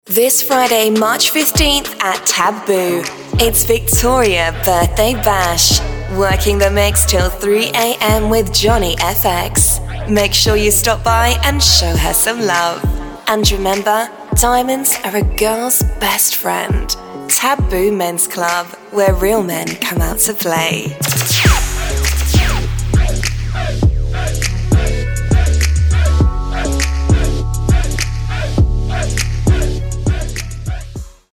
Events & Party Voiceover
A: Yes. You’ll receive the voiceover with and without background music.
Britsh-party-promo.mp3